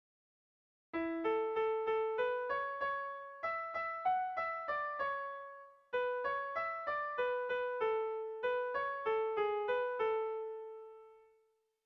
Irrizkoa
Lauko txikia (hg) / Bi puntuko txikia (ip)
AB